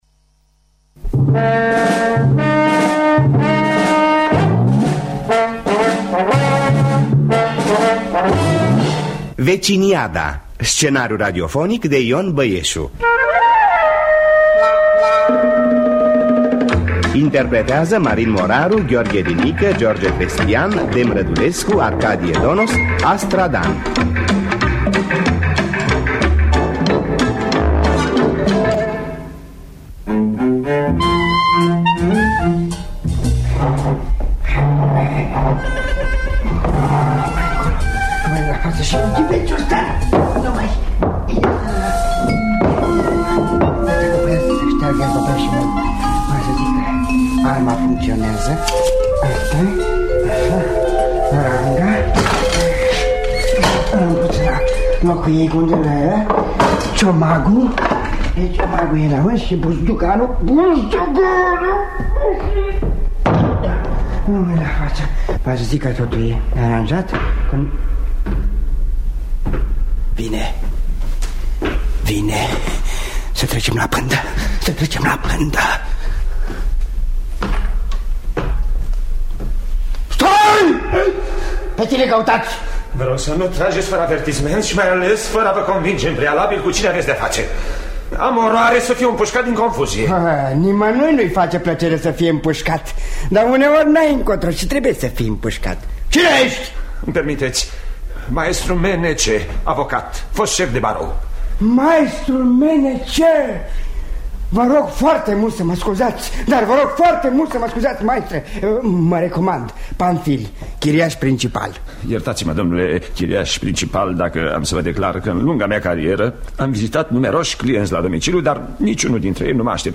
Veciniada de Ion Băieşu – Teatru Radiofonic Online